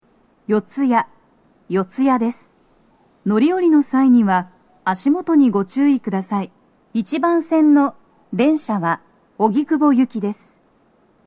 女声
到着放送2
TOA弦型での収録です。